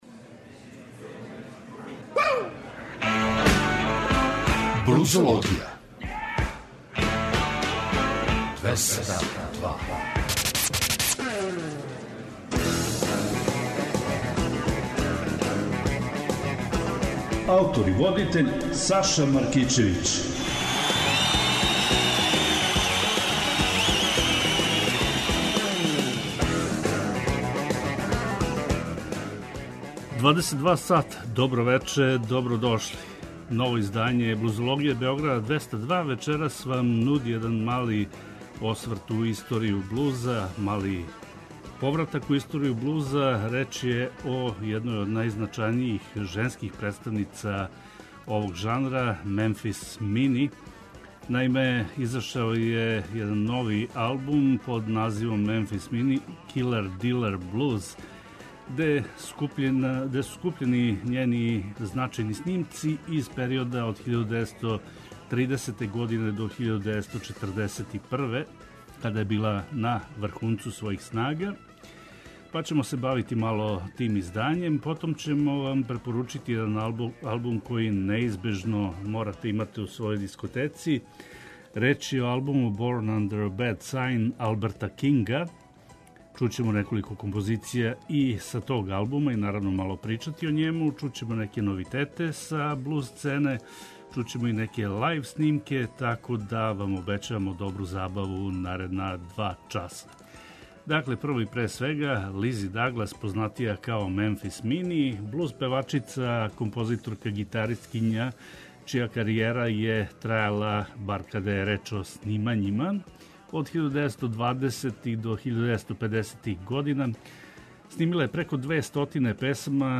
Нудимо вам избор нових музичких издања из овог жанра.